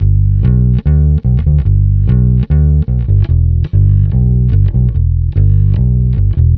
Twisting 2Nite 6 Bass-G.wav